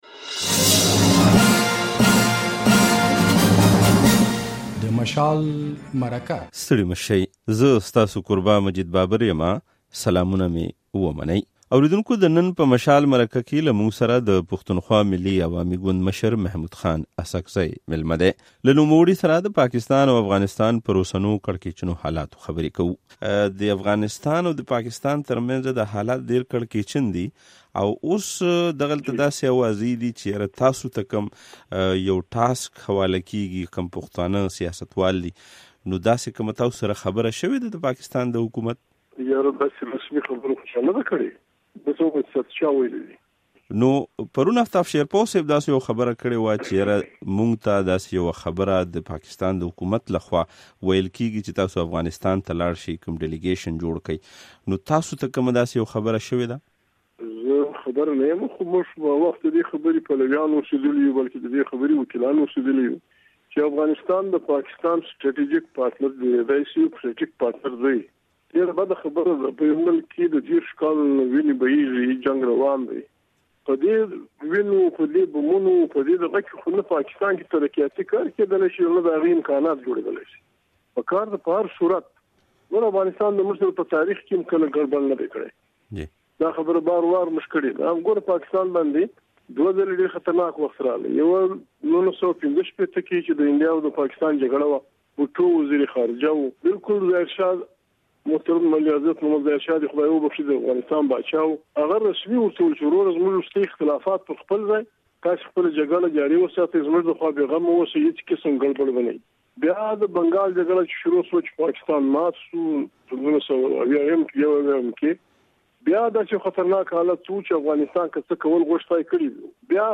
د ده په خبره که دوی په افغانستان کې د امن په راوستو کې مرسته و نه کړه نو بيا د اقتصادي راهدارۍ په ګډون يوه پرمختيايې پروژه هم نه شي کاميابېدلی. د محمود خان اڅکزي څرګندونې په دې غږیزه مرکه کې اورېدلای شئ: